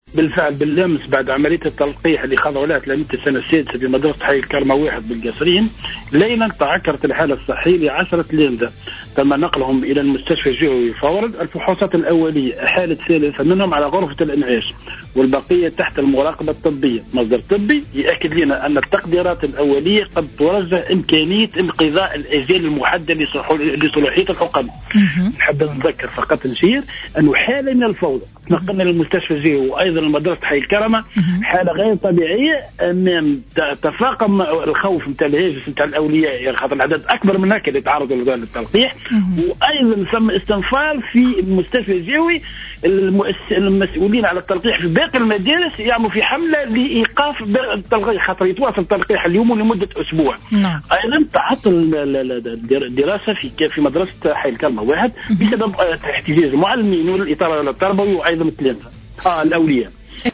Intervenu ce mercredi sur les ondes de Jawhara Fm